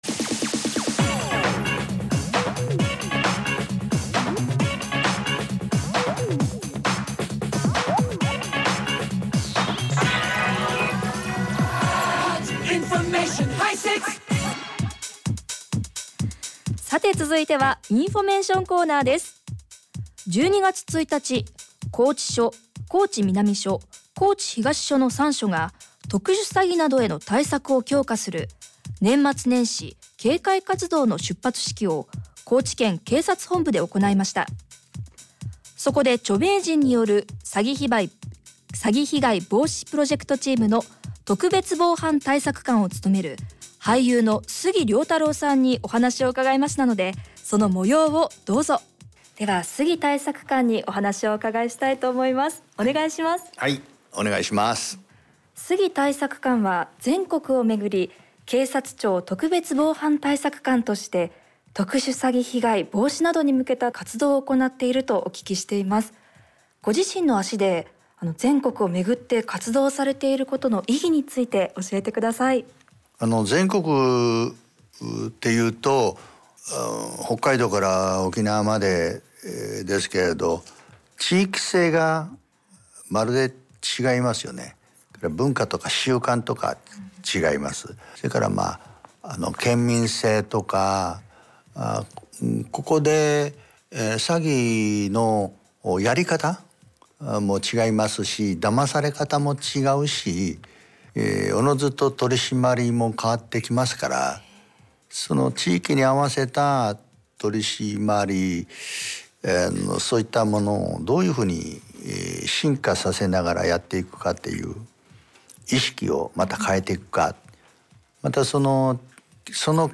FMラジオによる杉良太郎警察庁特別防犯対策監インタビュー （令和４年12月１日、高知県警察本部） ↓ 音声ファイル fm radio.mp3[MP3：10MB]